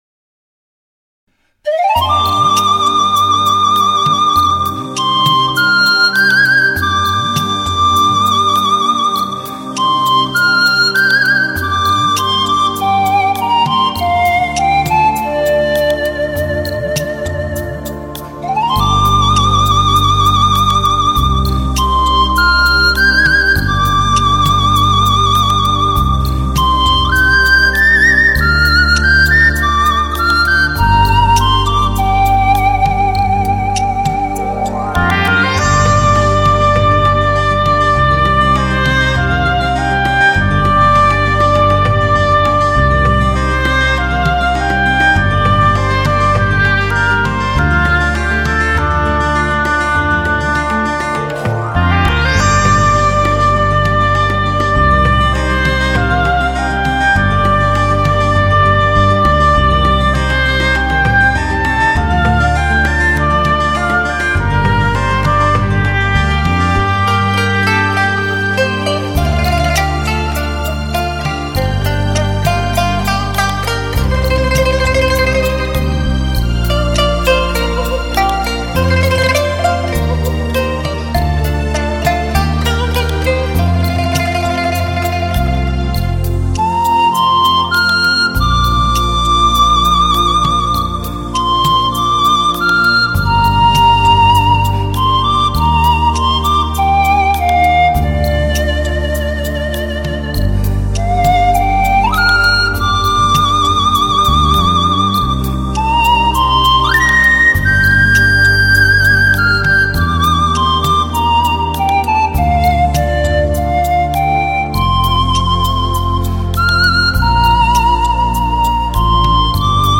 中国原创新世纪写景音乐